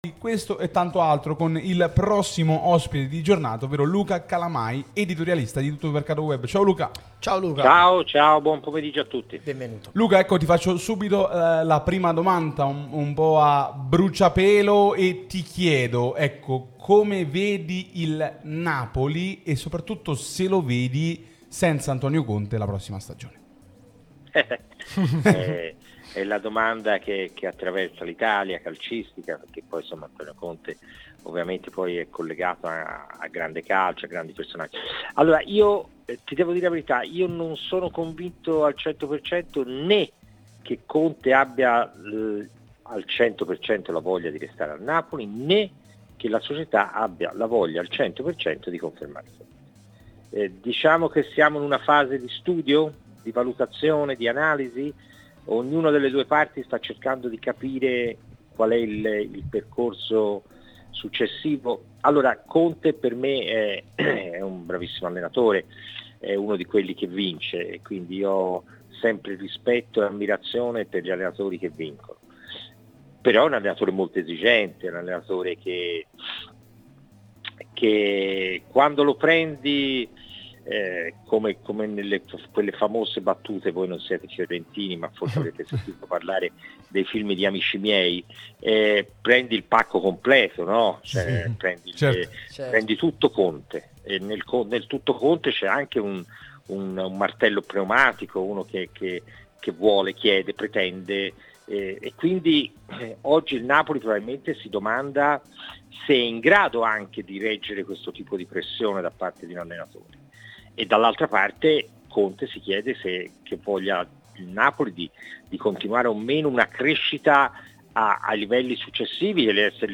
firma storica de La Gazzetta dello Sport